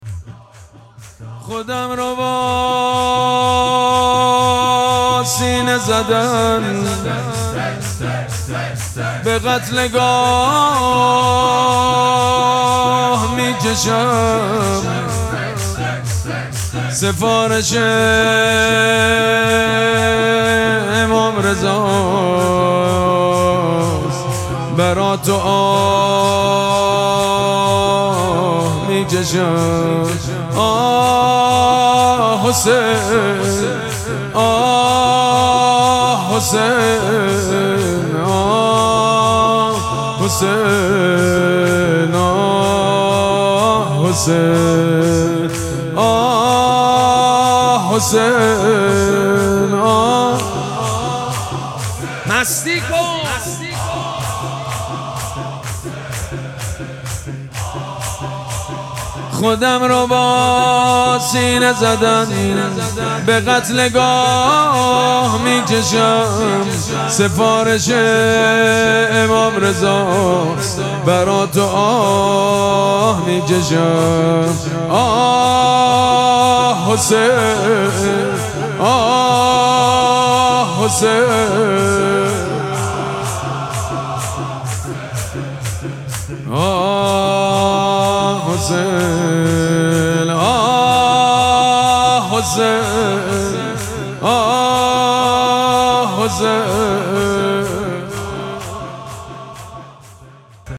مراسم مناجات شب هشتم ماه مبارک رمضان
حسینیه ریحانه الحسین سلام الله علیها
شور
مداح
حاج سید مجید بنی فاطمه